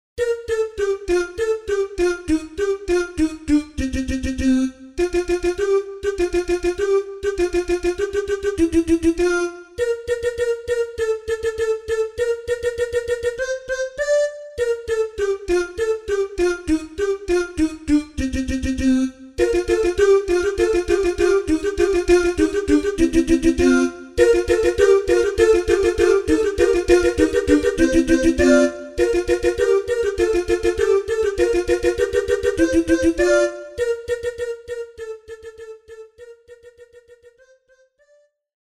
CANONS